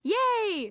Astronomer_Yay_1.mp3